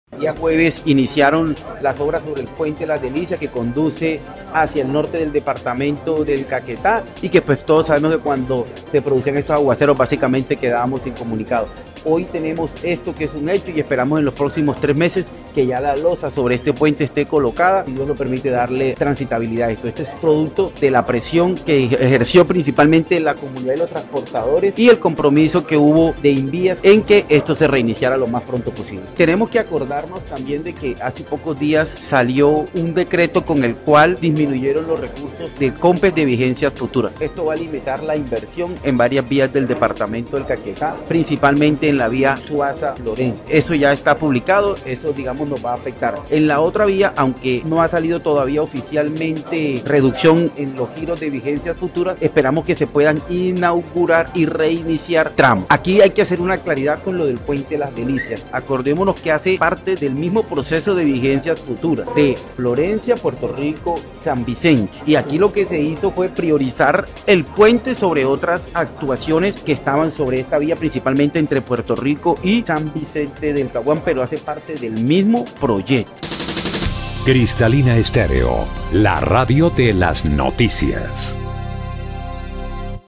El anuncio lo hizo el gobernador de Caquetá, Luis Francisco Ruiz Aguilar.
03_GOB_RUIZ_AGUILAR_DELICIAS.mp3